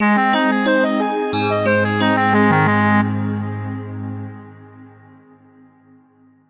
Because of this, even if it is digital synthesis, it sounds "warm".
All these demos are recorded directly from the ZynAddSubFX without audio processing with another program (well, exeption cutting/ OGG Vorbis compressing).